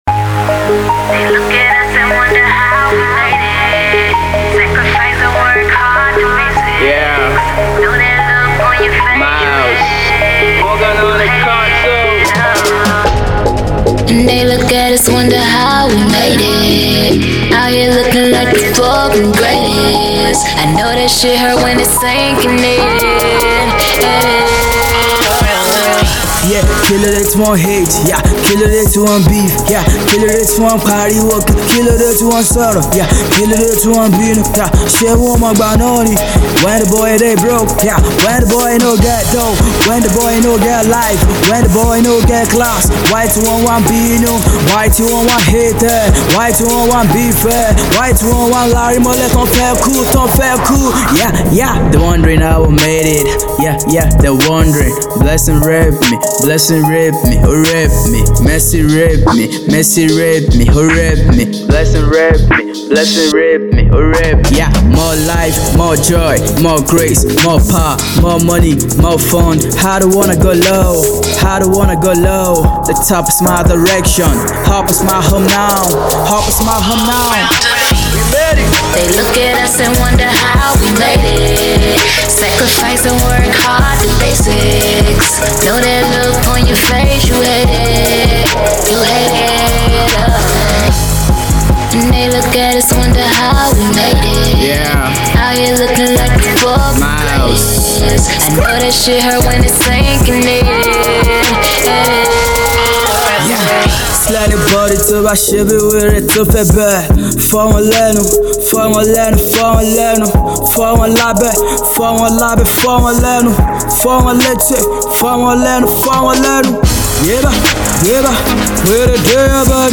The r&b hip pop artist came in on another style